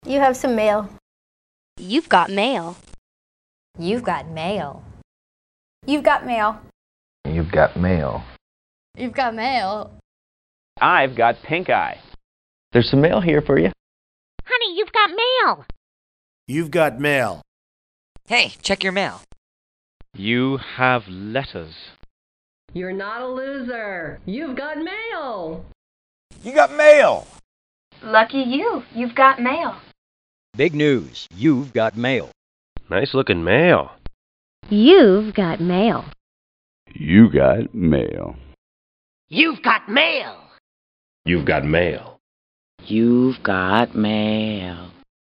Clicking this option and going to a drop-down menu, I found that there are multiple celebrities who provide their voice for the “You’ve Got Mail” notification sound. Some of the voices don’t stick to the script.